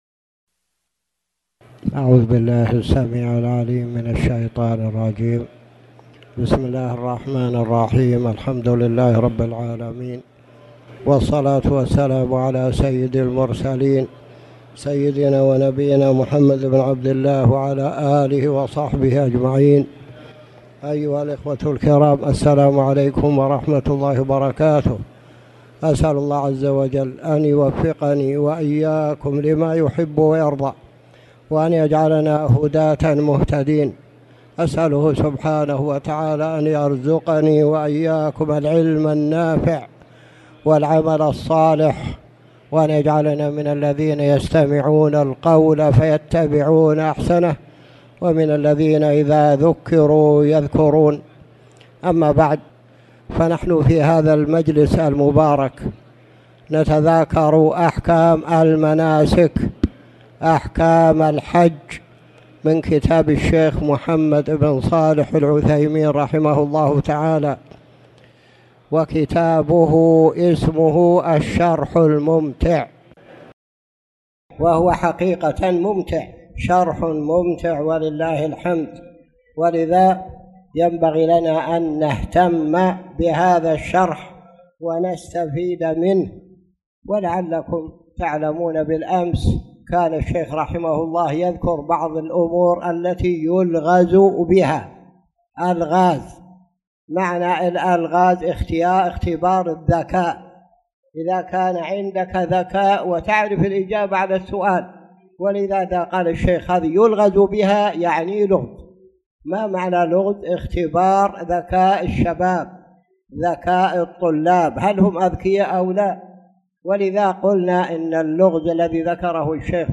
تاريخ النشر ١ ذو القعدة ١٤٣٨ هـ المكان: المسجد الحرام الشيخ